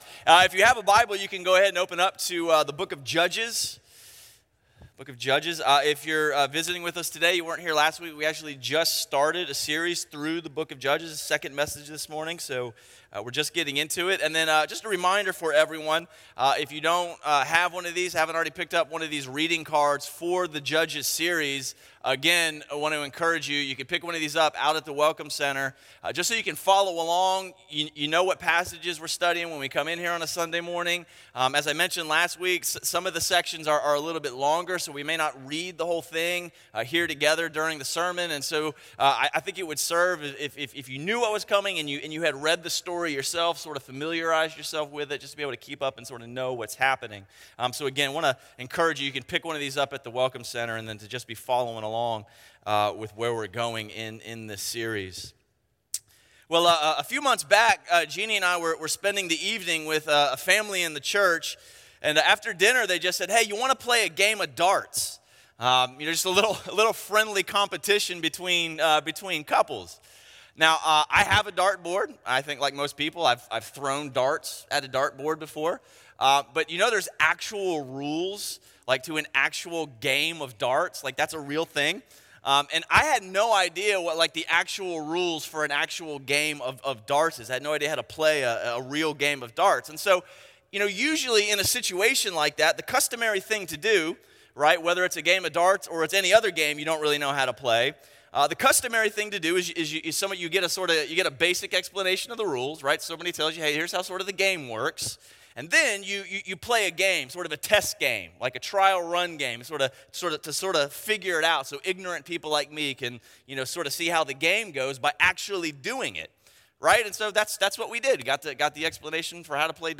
A message from the series "Christmas 2019."